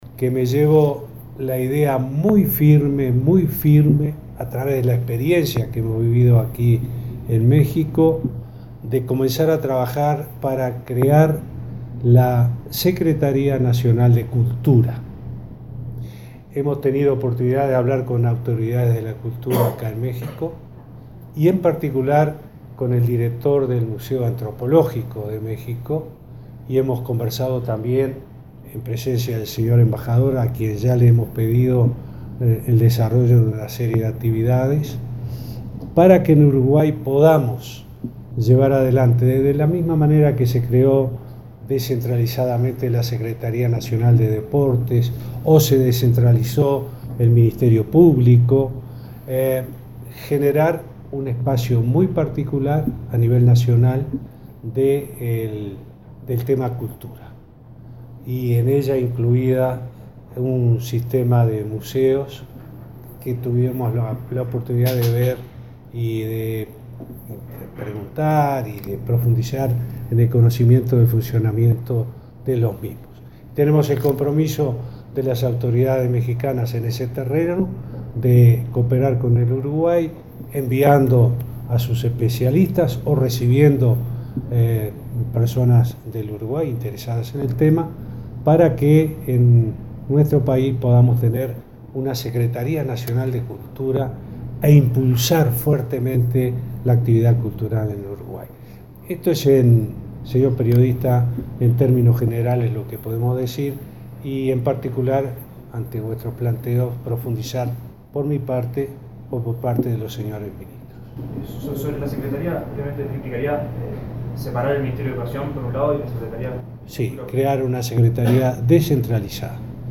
“Me llevo la idea muy firme de crear una Secretaria Nacional de Cultura”, afirmó el presidente Tabaré Vázquez, en el marco de su gira por México. Agregó que existe el compromiso de las autoridades mexicanas de cooperar en este proyecto.